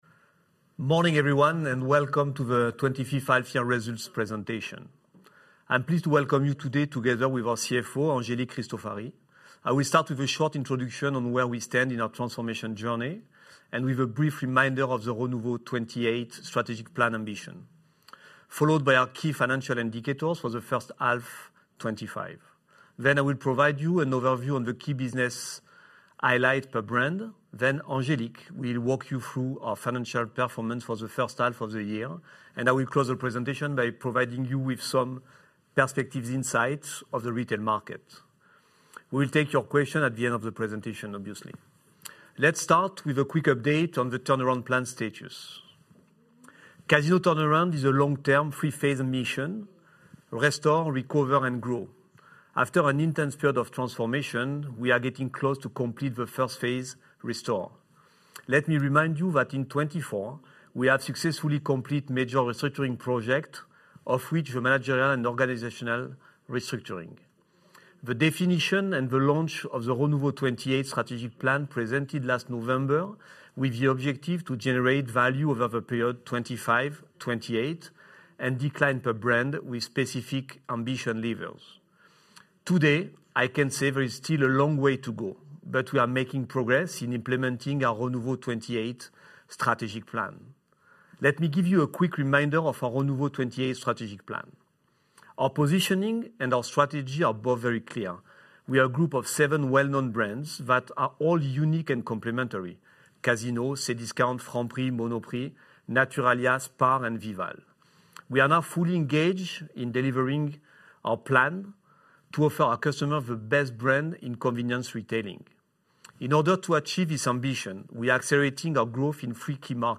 Pour réécouter la conférence téléphonique